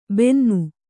♪ bennu